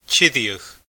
Pronunciation[ˈtʲʰiɾʲəɣ]